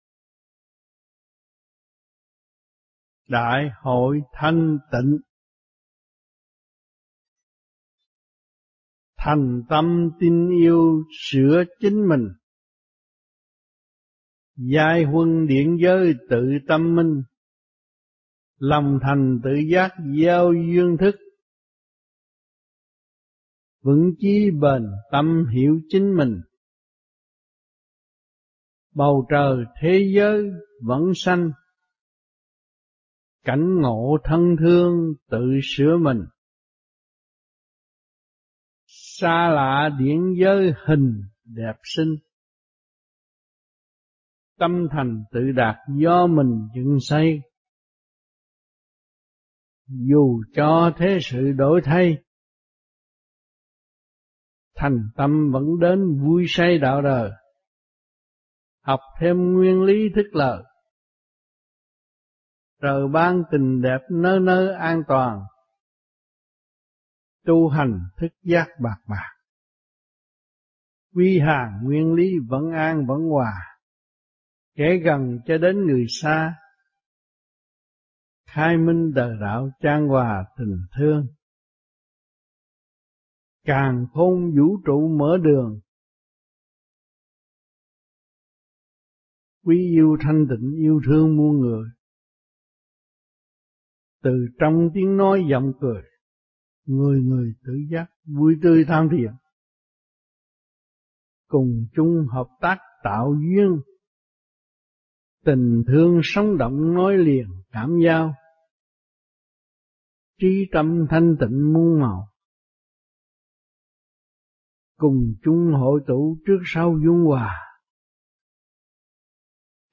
2000-07-17 - Prague - ĐHVVQT Kỳ 19 - Đại Hội Thanh Tịnh
Băng Giảng